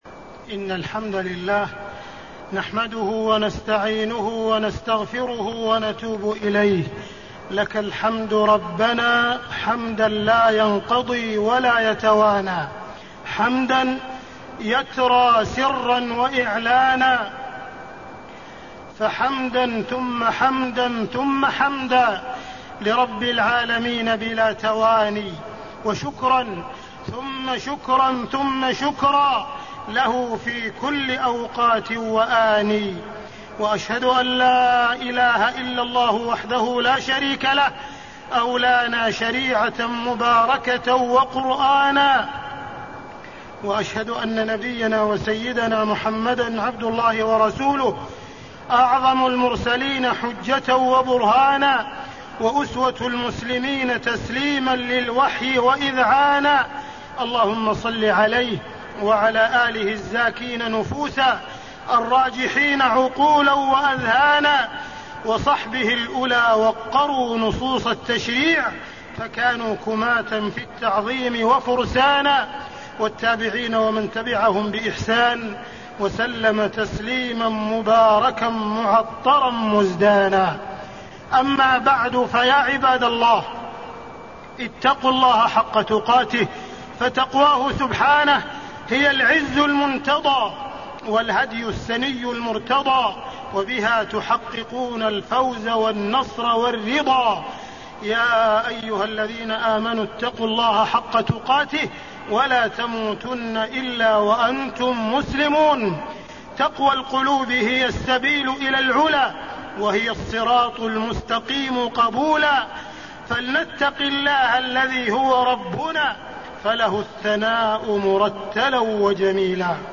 تاريخ النشر ٢٨ رجب ١٤٣٤ هـ المكان: المسجد الحرام الشيخ: معالي الشيخ أ.د. عبدالرحمن بن عبدالعزيز السديس معالي الشيخ أ.د. عبدالرحمن بن عبدالعزيز السديس خطورة تقديم العقل على النقل